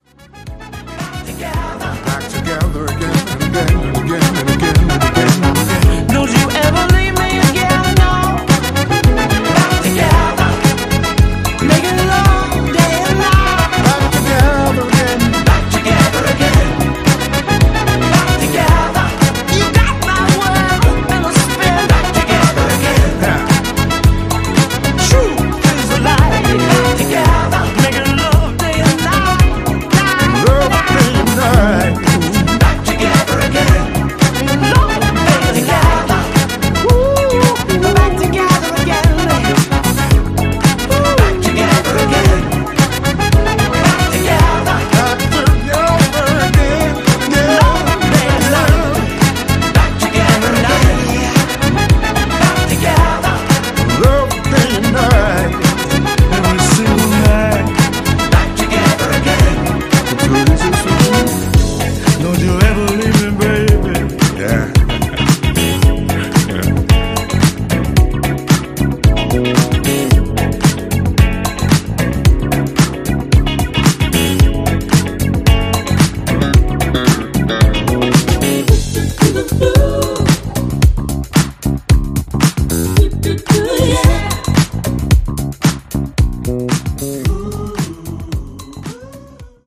Disco Mix